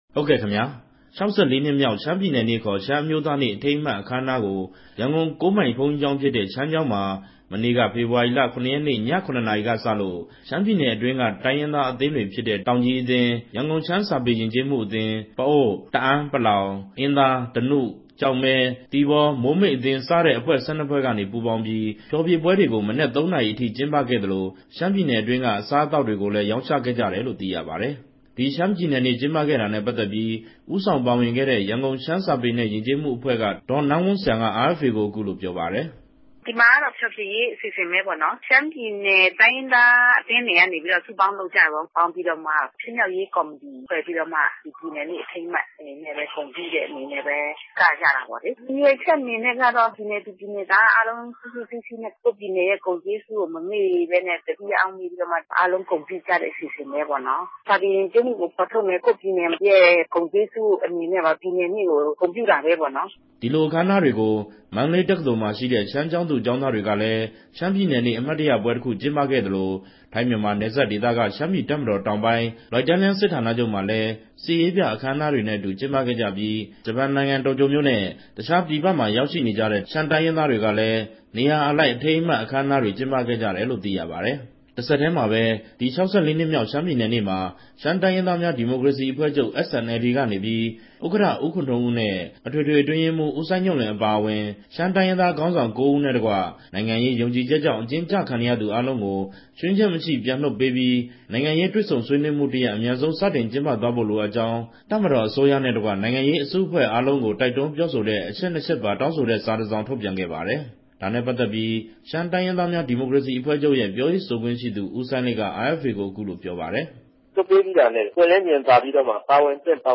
သတင်းပေးပို့ချက်